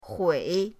hui3.mp3